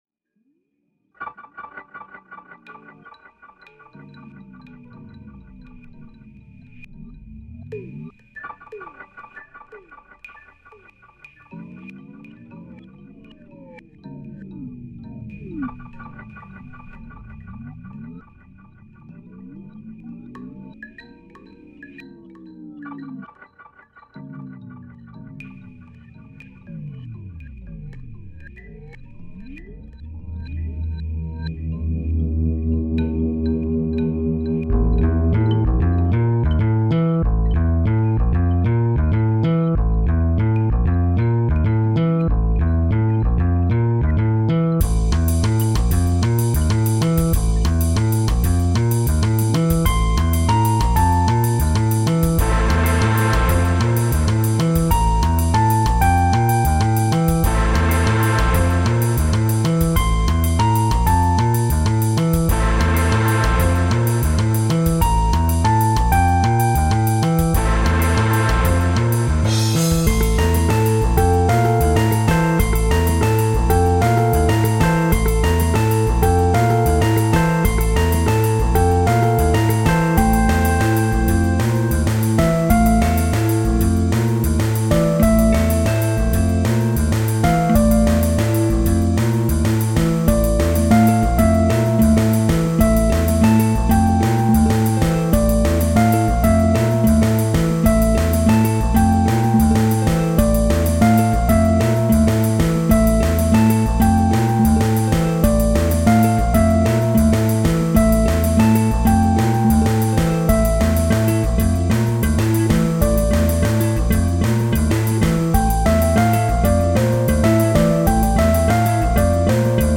guitarra, teclados, juguetes y objetos